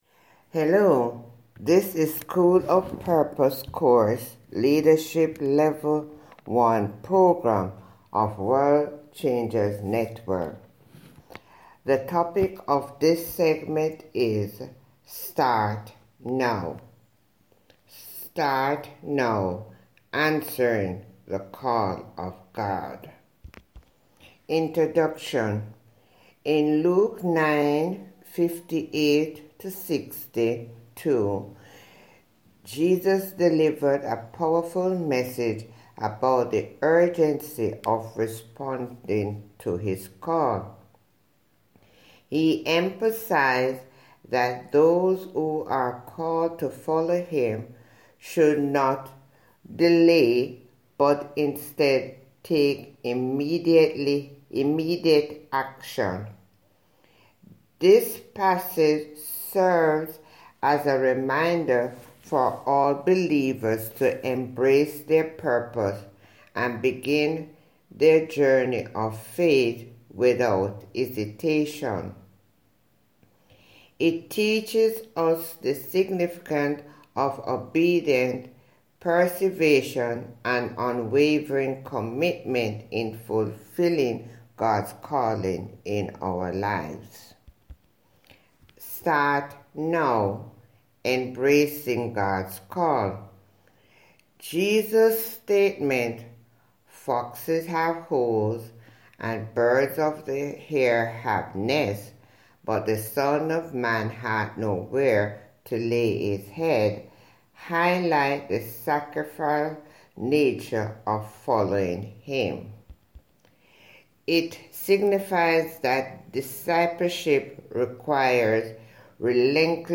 Listen to human voice reading here: